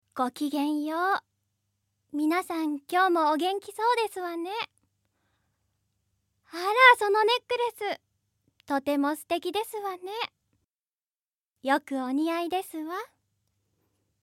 Voice
セリフ1